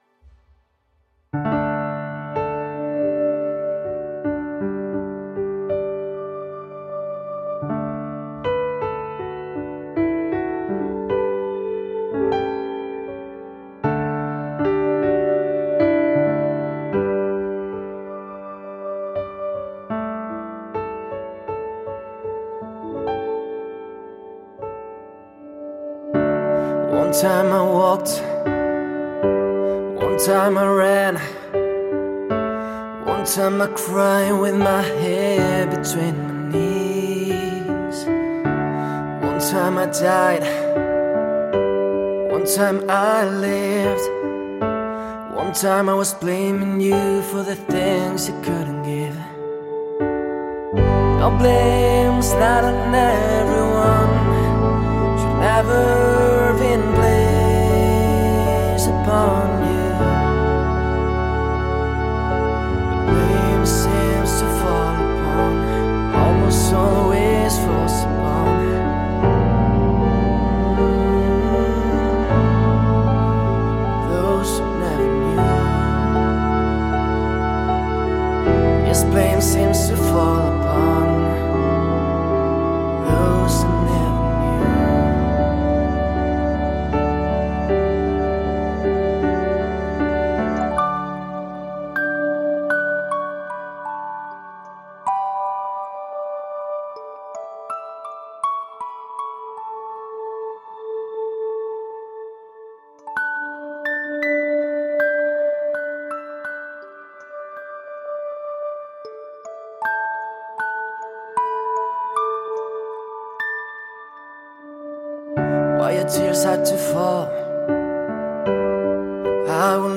Very relaxing music ,, good job…